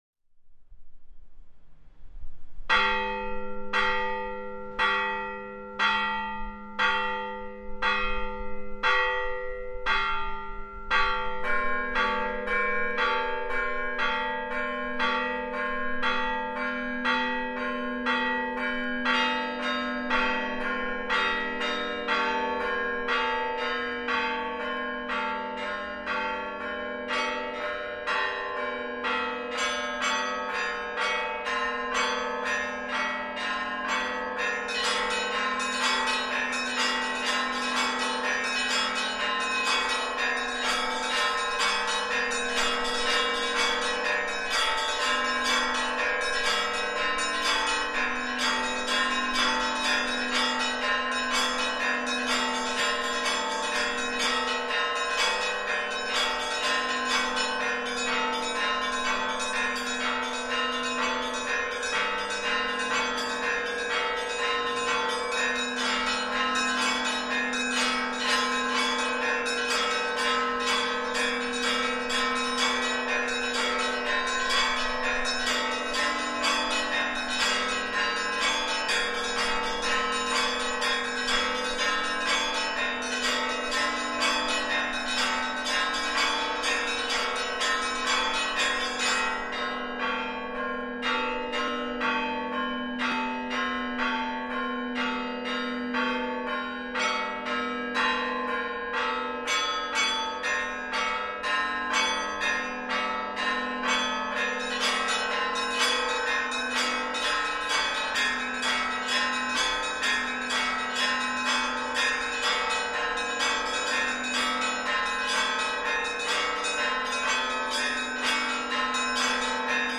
kolokola-cerkovnyy-zvon.mp3